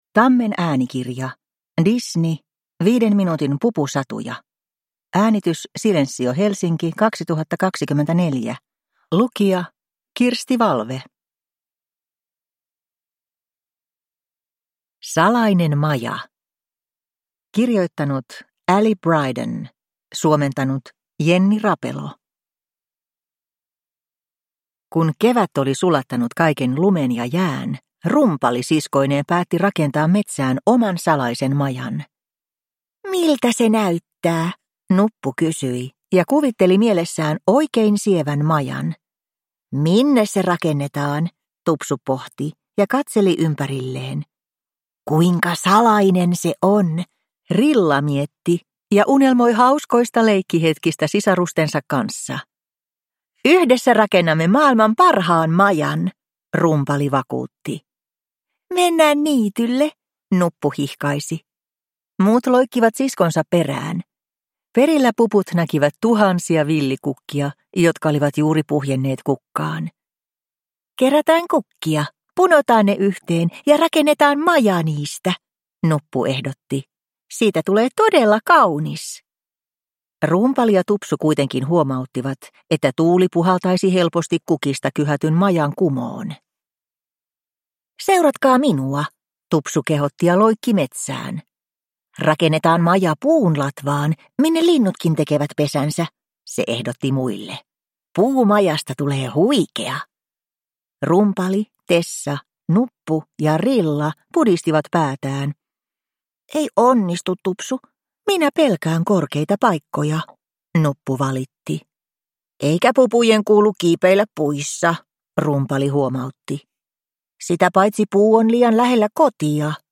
Disney Puput. 5 minuutin pupusatuja – Ljudbok